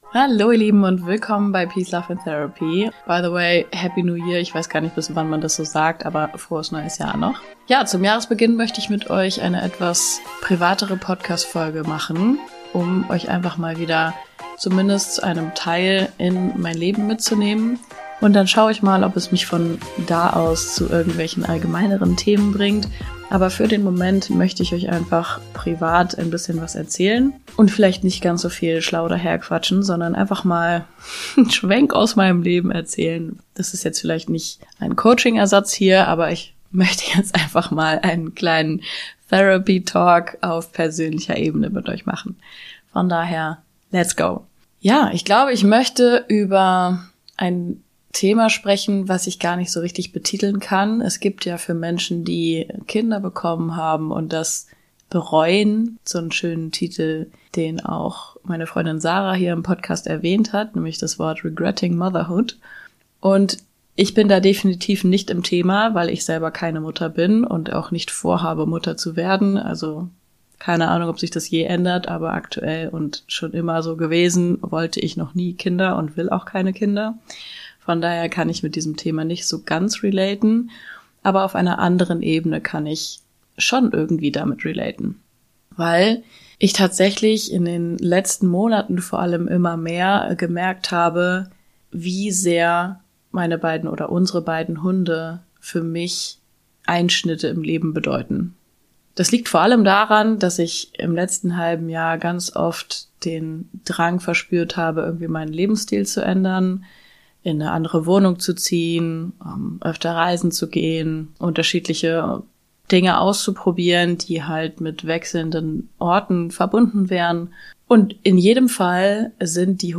Zum Jahresbeginn starten wir direkt mal mit einer sehr persönlichen Podcastfolge zu Themen, die mich in den letzten Monaten privat beschäftigt haben. Diese Folge ist ungeskriptet, direkt aus dem Leben und so unperfekt, wie mein Lebensplan.